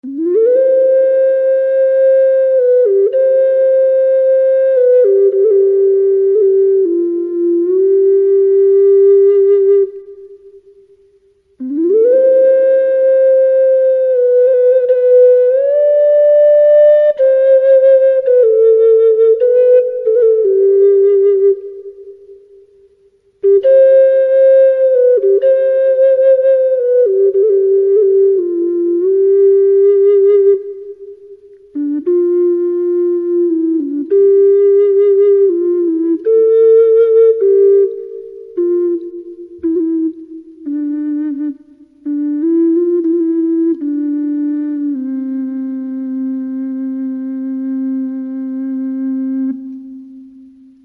音符をクリックすると管理人のデモ演奏が聴けます。
だいぶリバーブに助けられちゃってます。
７Ｃ管。バスＣ管とも呼んだりします。上のオカリナより１オクターブ低い。
bass-C-oca-samplemusic.mp3